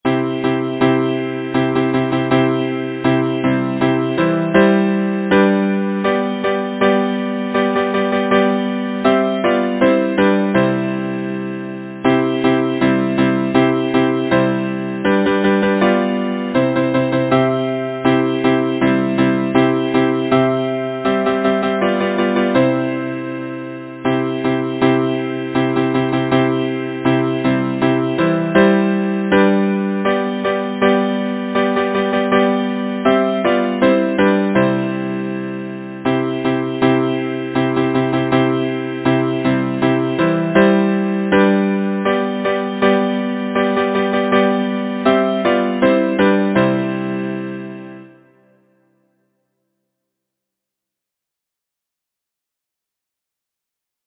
Title: Lovely May Composer: Benjamin Carl Unseld Lyricist: Aldine Silliman Kieffer Number of voices: 4vv Voicing: SATB Genre: Secular, Partsong
Language: English Instruments: A cappella